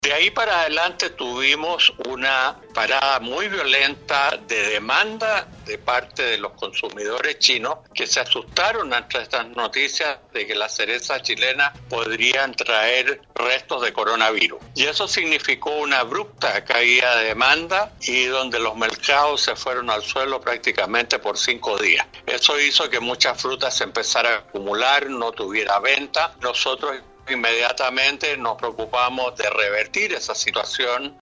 En conversación con Radio Sago, el Embajador de Chile en China, Luis Schmidt, comentó que el argumento que presentaron los compradores Chinos de la cereza Chilena, dice relación con un posible ingreso de coronavirus a través de la fruta Chilena al país asiático, indicando que la exportación realizada hasta fines de enero, su pago en su valor real está asegurado para los productores Chilenos. El Embajador fue categórico en señalar que se están realizando todas las gestiones en China para revertir esa medida de restricción y baja de demanda y precios que se dio a conocer recientemente a objeto de que los compradores en dicho país puedan volver a adquirir dicho producto Chileno y con valores reales.